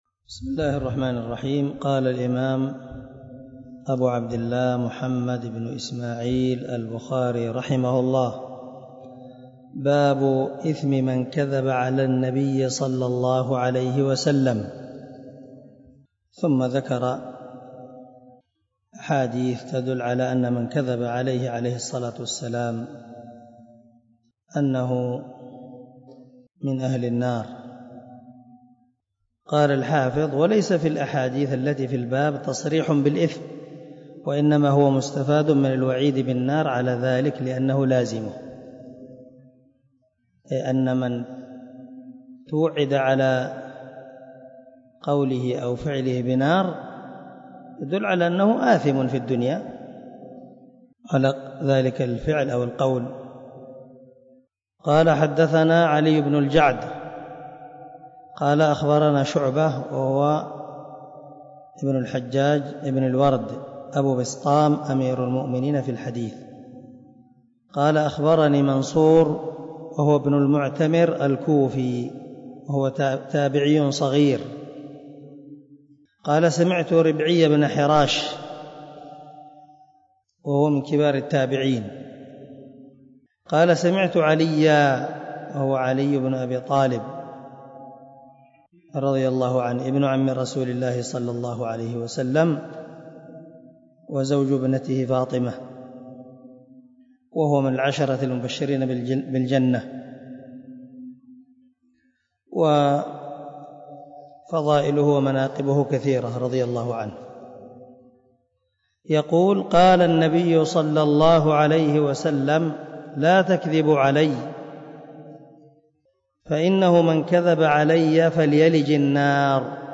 101الدرس 46 من شرح كتاب العلم حديث رقم ( 106 ) من صحيح البخاري
دار الحديث- المَحاوِلة- الصبيحة.